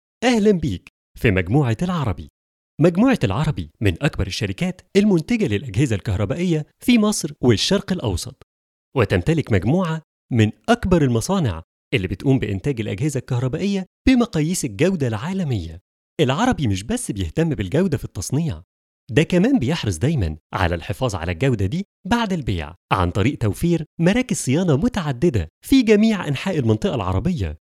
Male
Yng Adult (18-29), Adult (30-50)
Tuneful, warm, deep voice
Documentary
Live Announcer
Radio Commercials